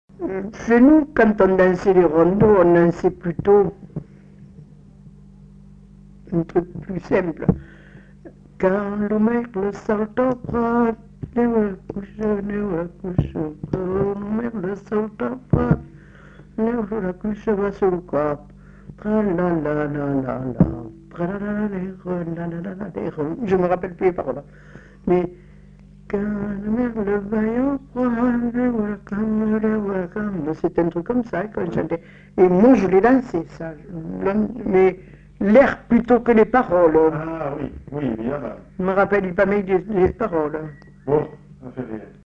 Aire culturelle : Bazadais
Lieu : Grignols
Genre : chant
Effectif : 1
Type de voix : voix de femme
Production du son : chanté
Danse : polka